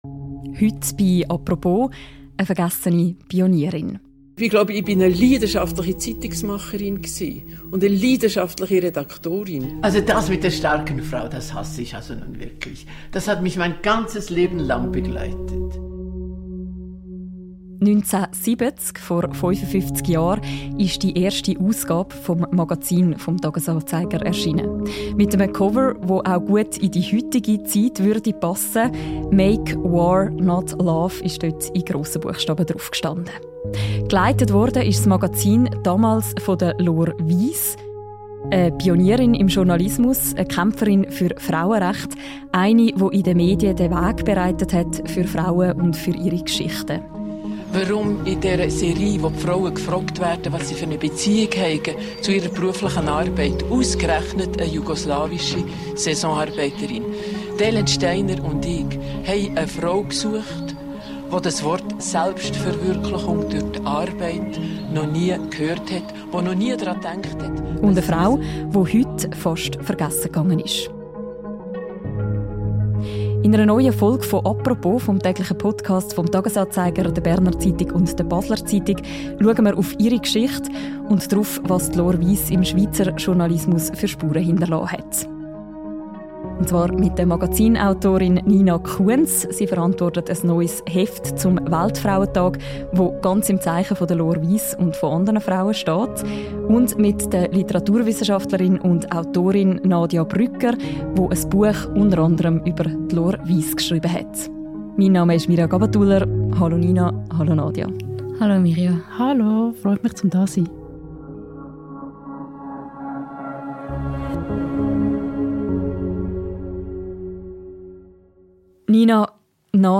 Diese Apropos-Folge enthält Tonmaterial aus der SRF Dokumentation Laure Wyss: Ein Schreibleben - Sternstunde Kunst aus dem Jahr 1999.